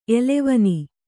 ♪ elevani